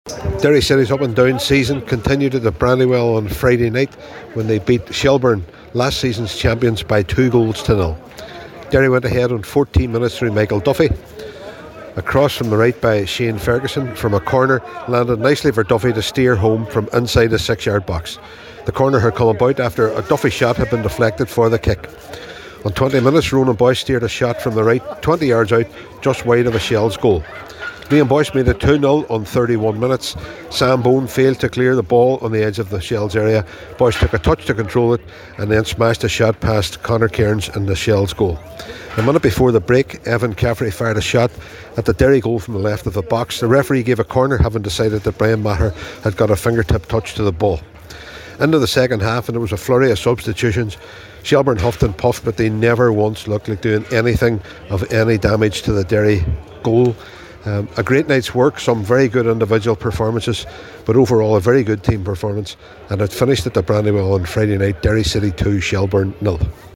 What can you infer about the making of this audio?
the full time report from the Brandywell…